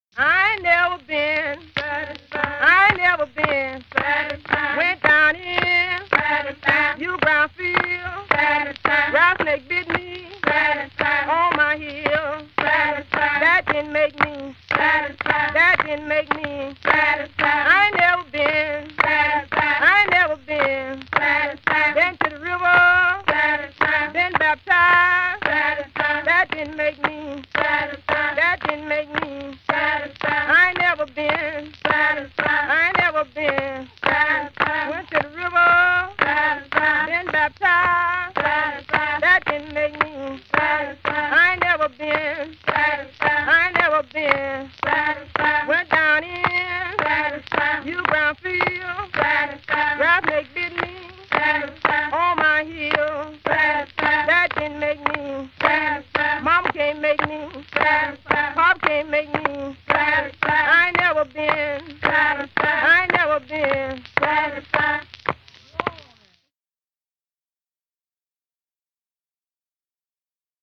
Field recordings paired with these images were recorded in rural Mississippi by John and Alan Lomax between 1934 and 1942.